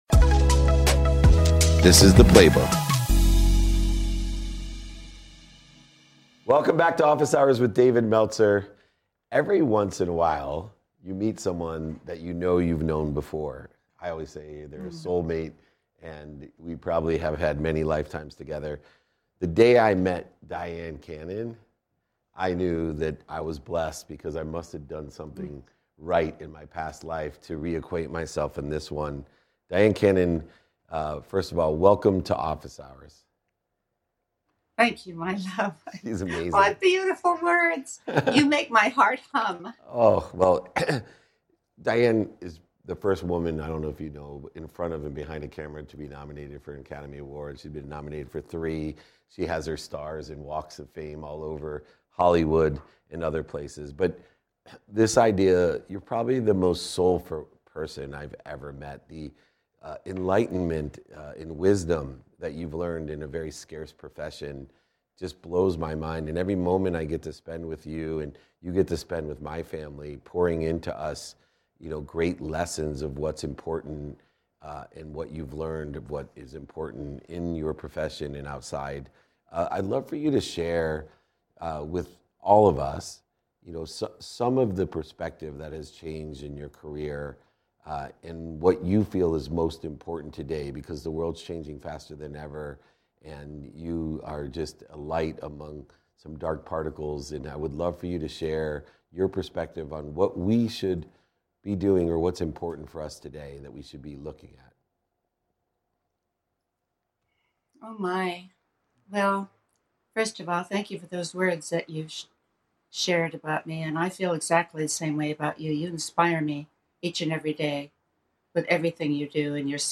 Breaking Barriers with Grace: A Candid Conversation with Dyan Cannon